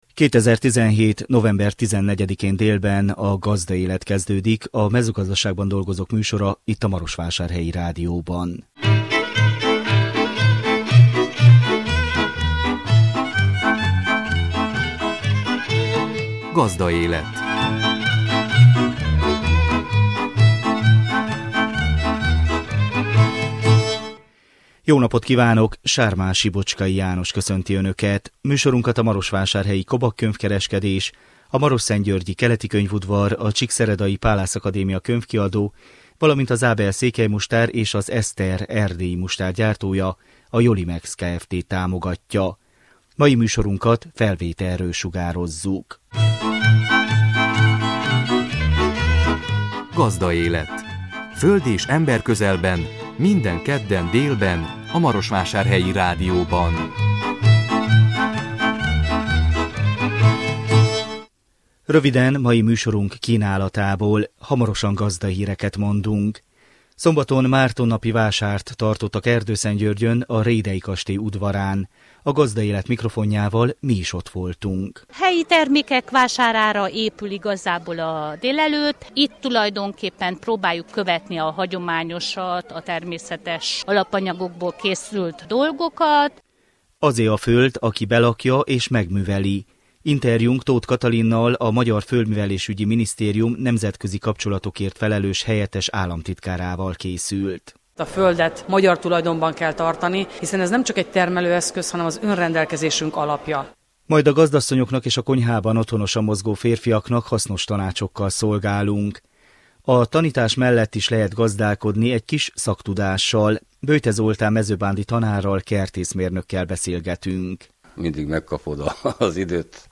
A 2017 november 14-én jelentkező műsor tartalma: Gazdahírek, Szombaton Márton napi vásárt tartottak Erdőszentgyörgyön a Rhédey kastély udvarán. A Gazdaélet mikrofonjával mi is ott voltunk.
Interjúnk Toth Katalinnal, a Magyar Földművelésügyi Minisztérium Nemzetközi kapcsolatokért felelős helyettes államtitkárával készült.